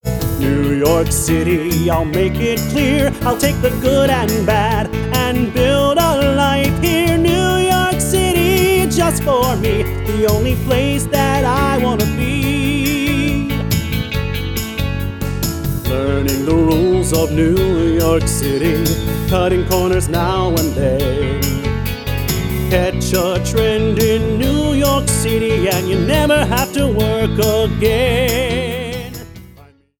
Vocal mp3 Track